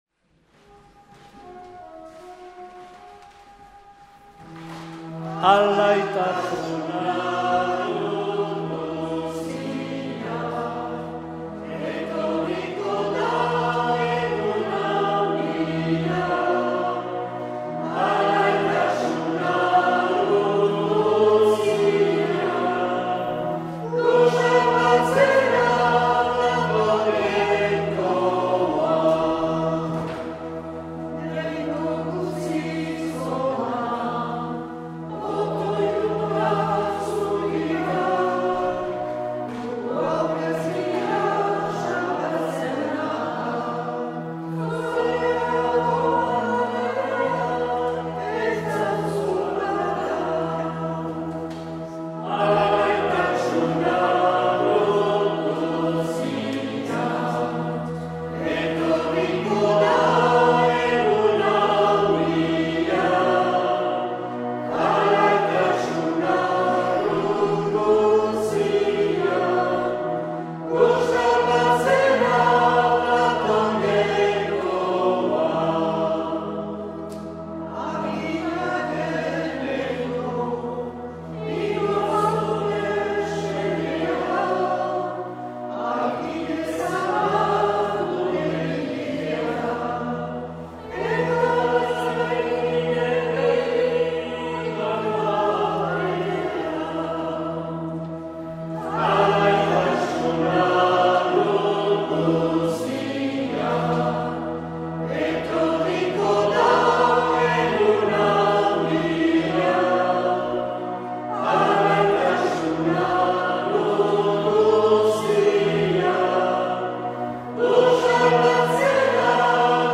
Accueil \ Emissions \ Vie de l’Eglise \ Célébrer \ Igandetako Mezak Euskal irratietan \ 2022-12-18 Abenduko 4.